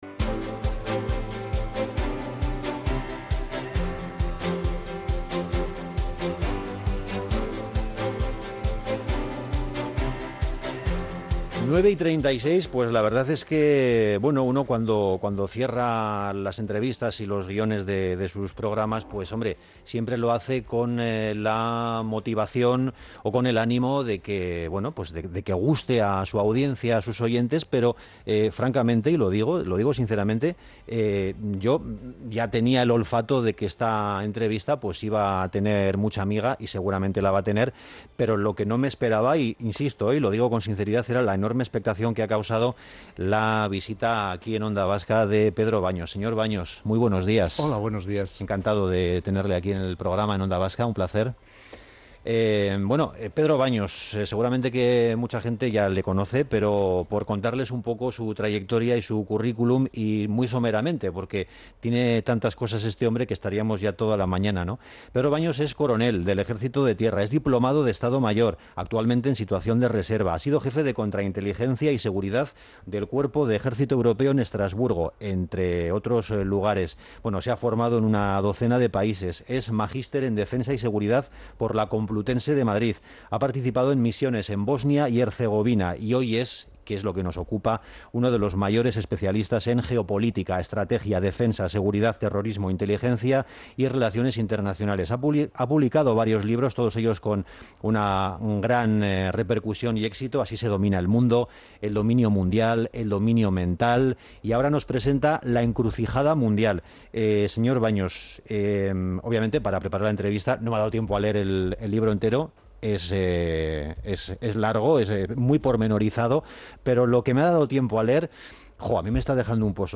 Entrevistas
Morning show conectado a la calle y omnipresente en la red.